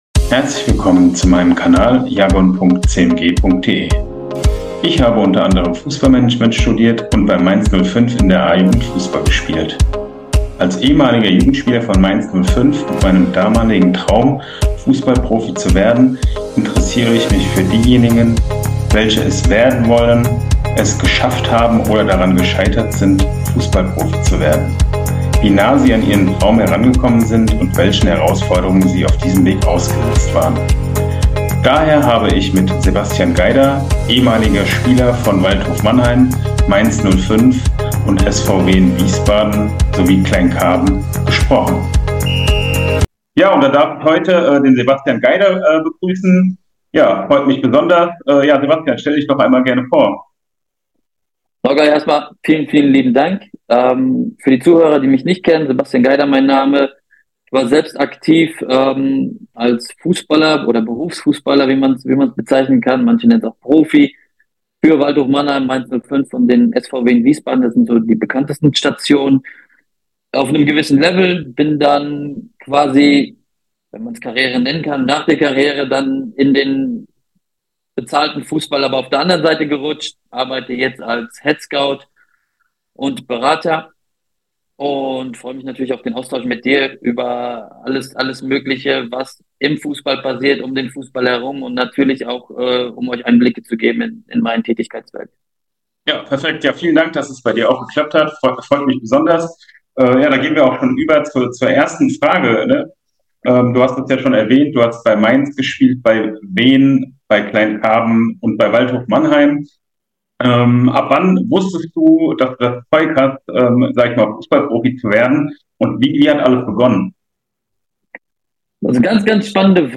Wenn euch das Podcast Interview gefällt, dann abonniert und bewertet gerne meinen Kanal auf Spotify, Amazon Music, Apple Podcasts oder Youtube.